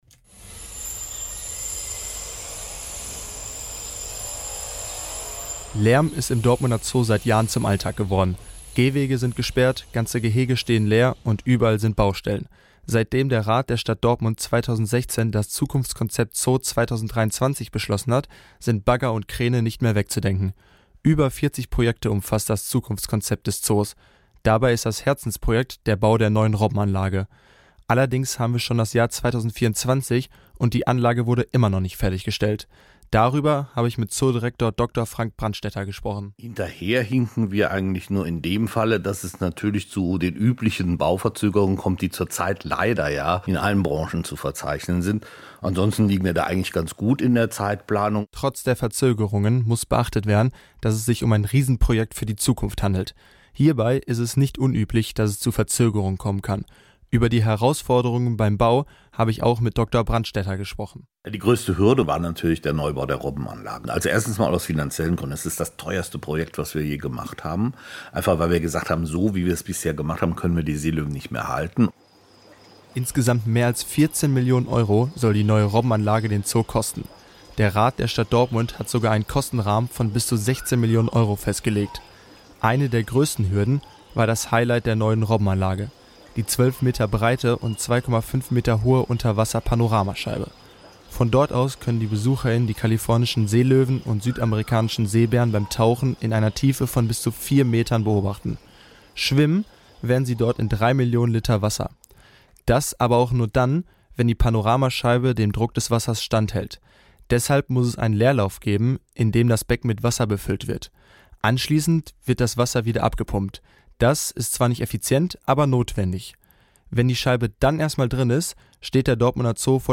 Serie: Beiträge